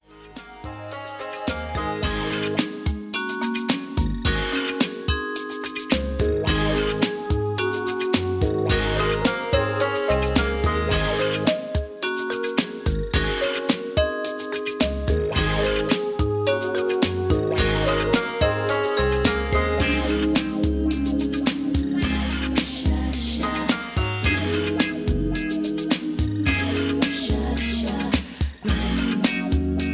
Key-Ab) Karaoke MP3 Backing Tracks
Just Plain & Simply "GREAT MUSIC" (No Lyrics).